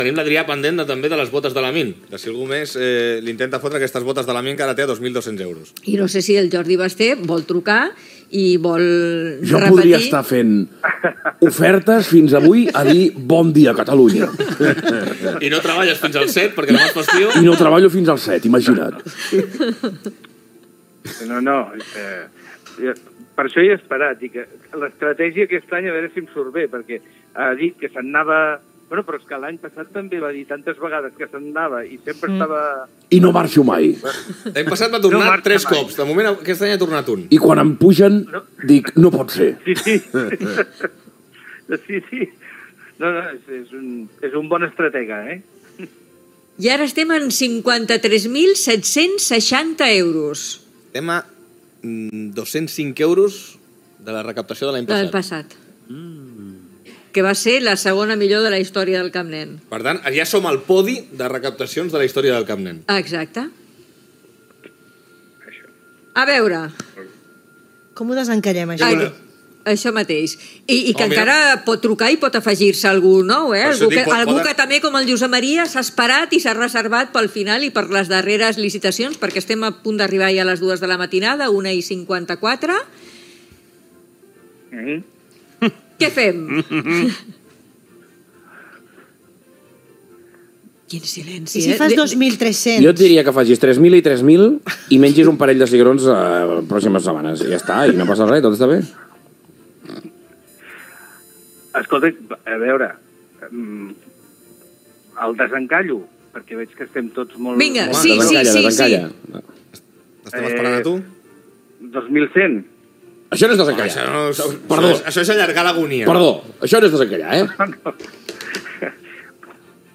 comiat i sintonia del programa Gènere radiofònic Participació